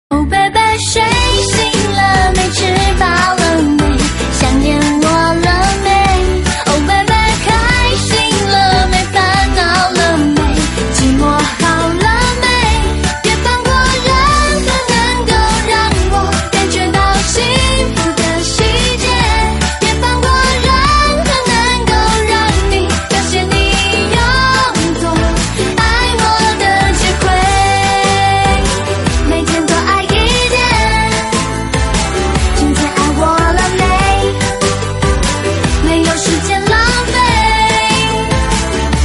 M4R铃声, MP3铃声, 华语歌曲 89 首发日期：2018-05-14 11:57 星期一